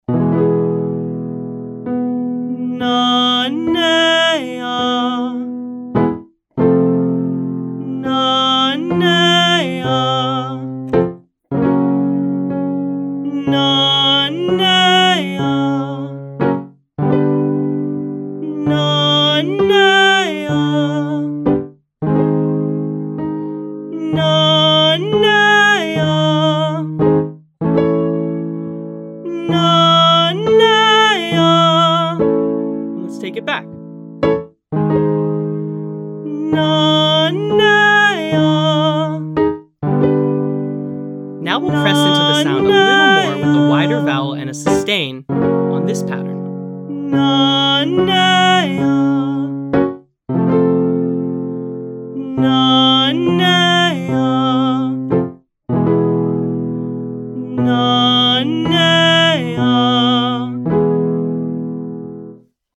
Belting
Exercise: Chest-dominant mix belt - Nuh, Ney, Uh 5-1-5  B
Now, we’ll press into the sound a little more with a wider vowel and a sustain, on.